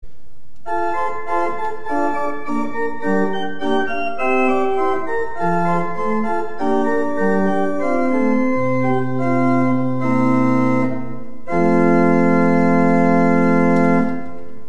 Hieronder kunt u diverse geluidsfragmenten van ons orgel beluisteren.
Holpijp 8', Roerfluit 4'
orgel-h8r4w2.mp3